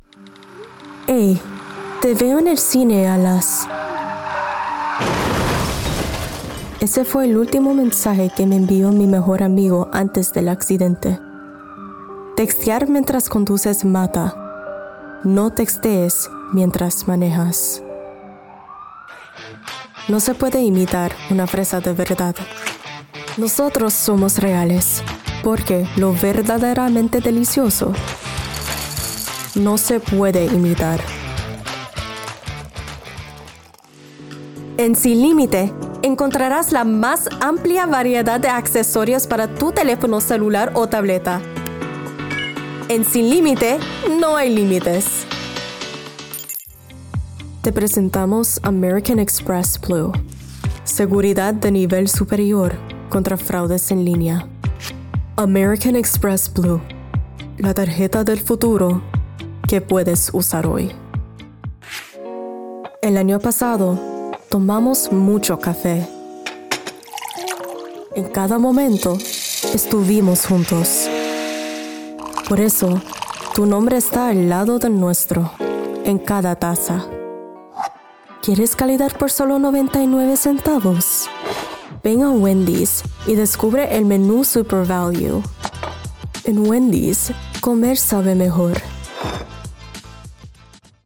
Commercial Demo
Spanish Commercial Demo